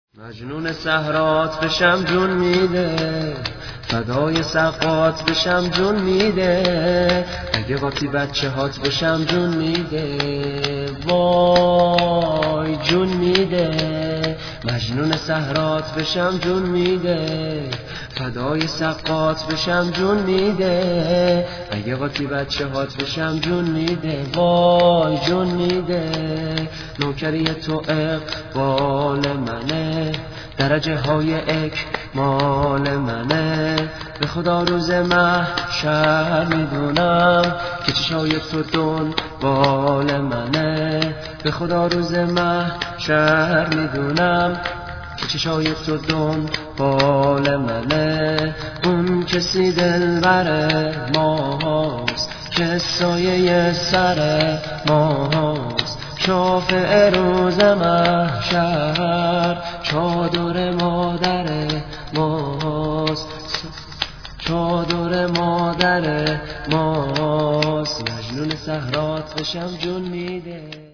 شور ، سرود